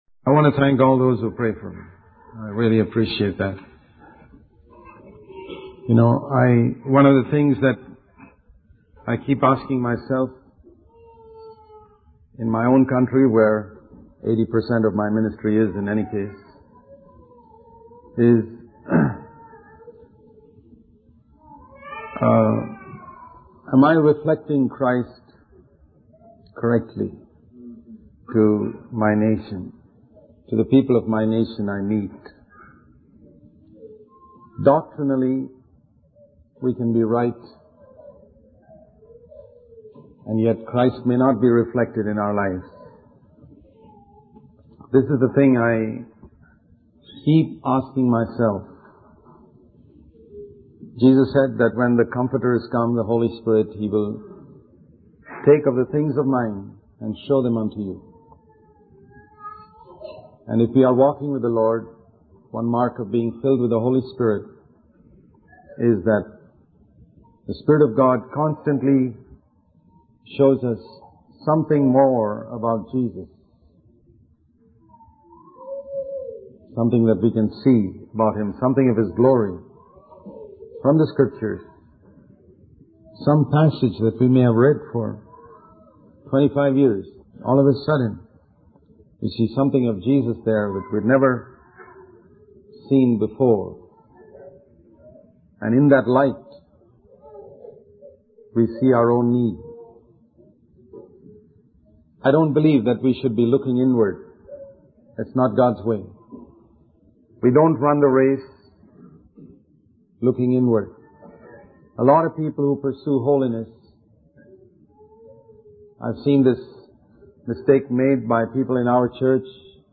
In this sermon, the speaker emphasizes the importance of giving words of encouragement to our loved ones. He challenges listeners to reflect on whether they are truly living like Christ and spreading a positive aroma through their actions and words.